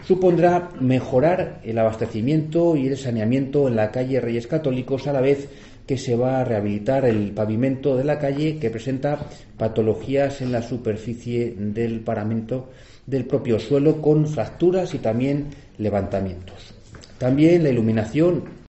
Los trabajos comenzarán de forma “inminente”, según ha informado el portavoz del equipo de Gobierno, José Ramón Budiño, que ha explicado así en que consistirán (ESCUCHAR).